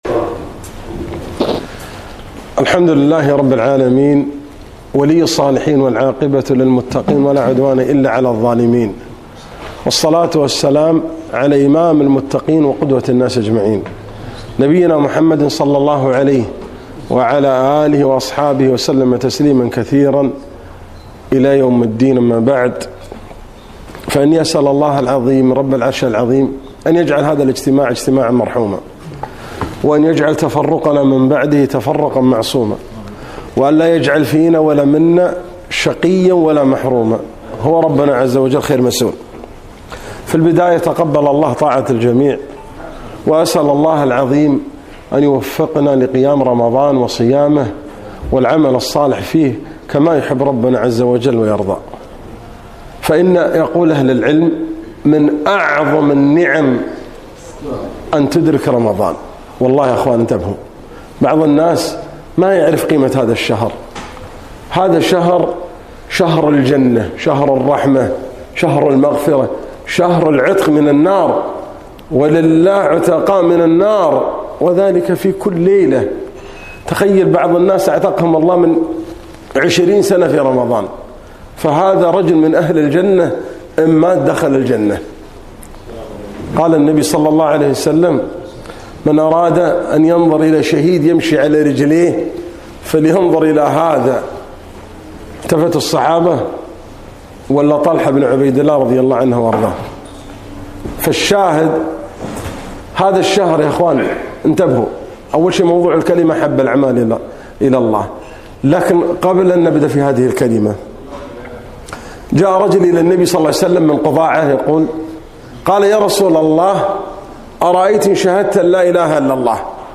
كلمة - أحب الأعمال إلى الله